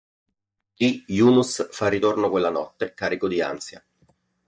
Pronounced as (IPA) /ˈnɔt.te/